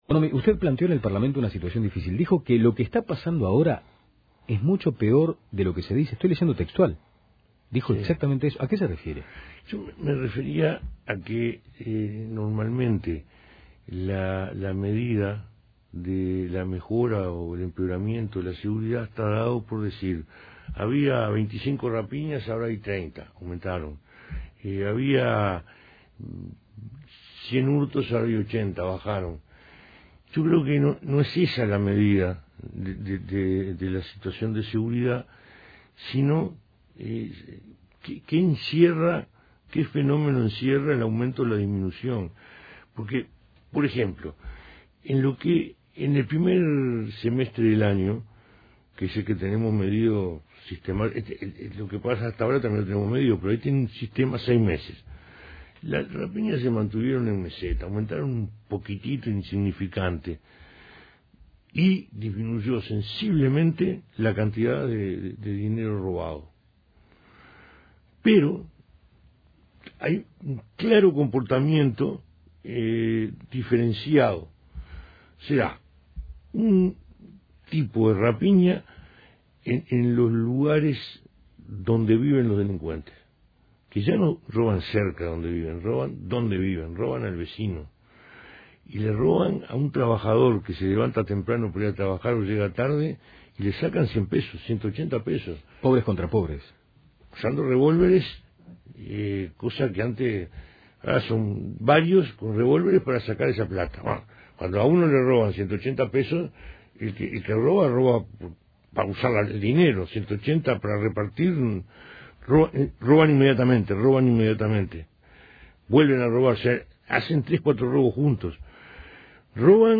Entrevista en La Mañana